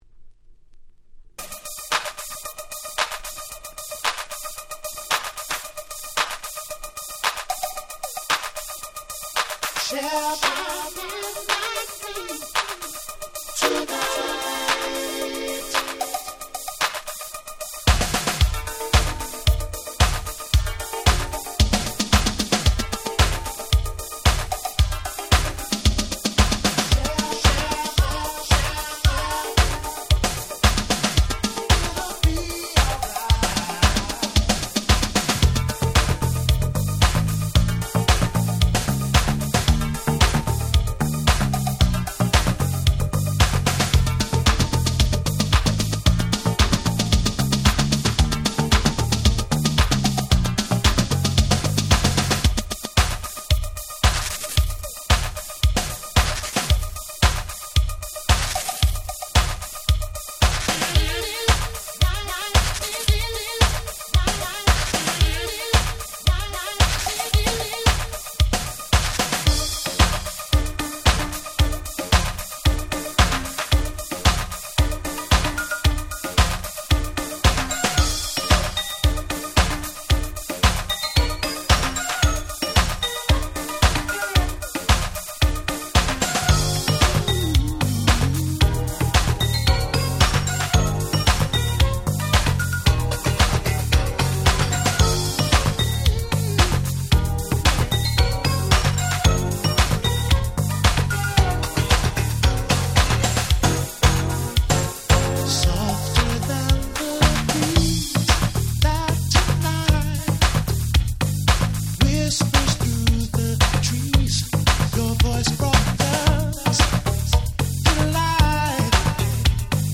88' Very Nice R&B / New Jack Swing !!
キラキラで軽快、超Groovyな大人のニュージャックスウィング！！
甘いヴォーカルも堪らない最高の1曲です！
80's Disco ディスコ NJS